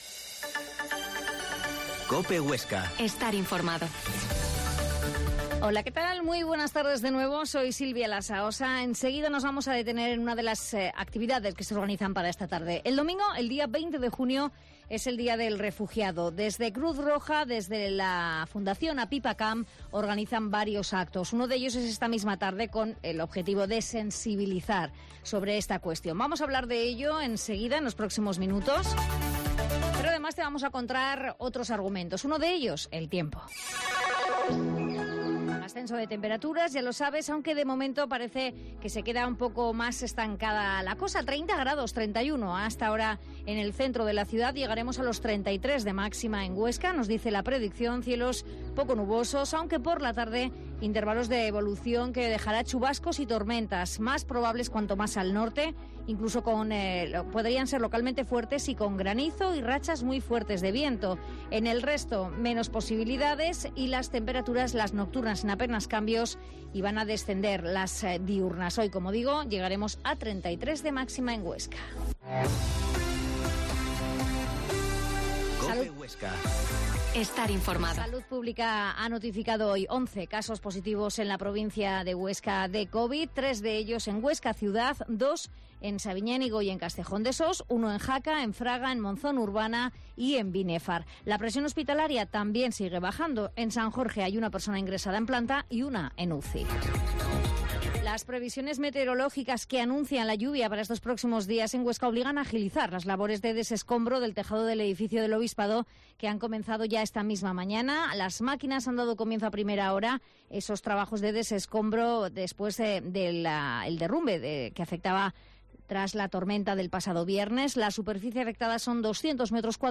Mediodía en Cope Huesca 13,20h. Entrevista